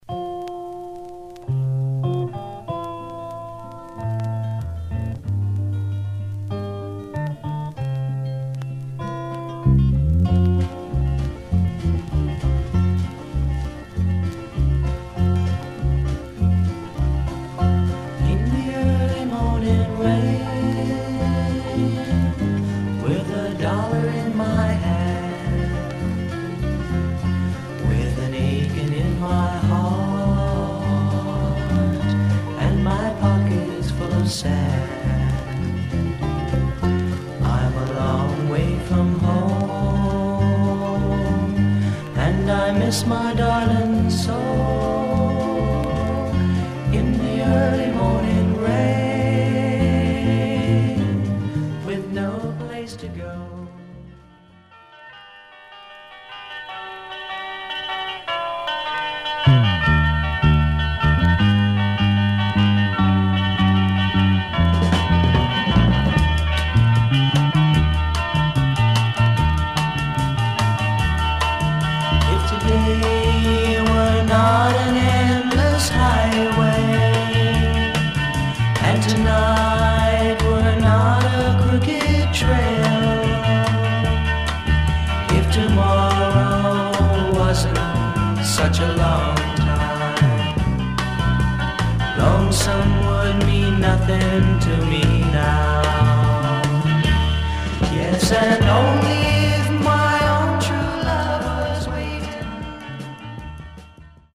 Mono
Rock